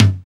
RX TOM LO.wav